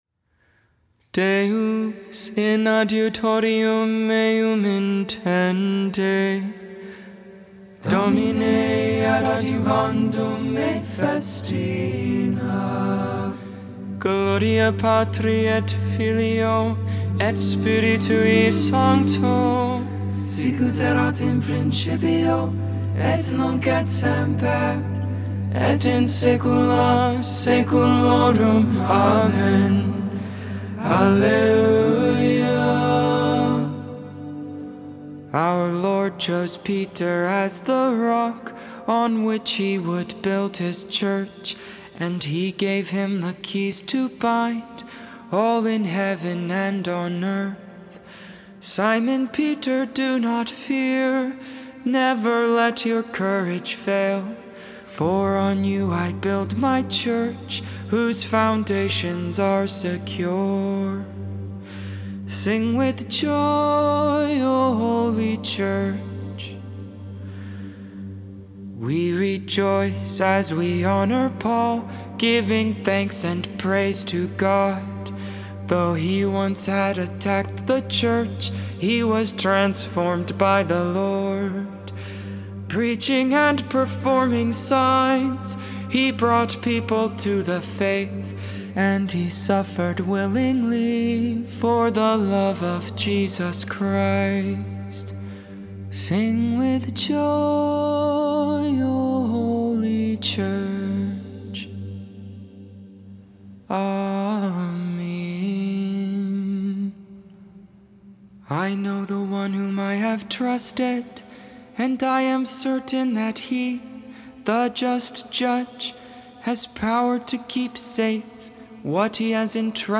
Syriac Hymn
Benedictus (English, Tone 8, Luke 1v68-79)